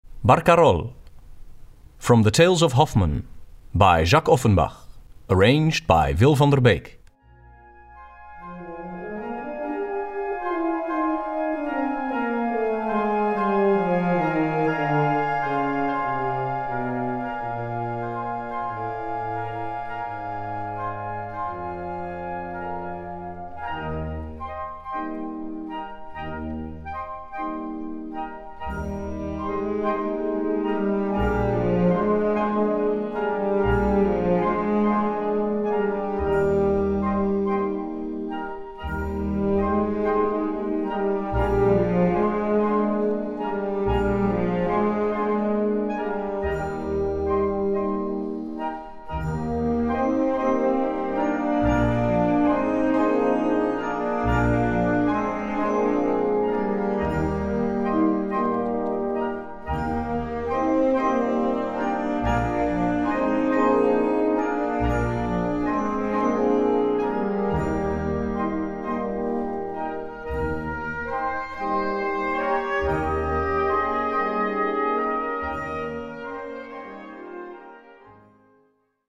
Gattung: Konzertante Blasmusik
Besetzung: Blasorchester